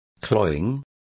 Complete with pronunciation of the translation of cloying.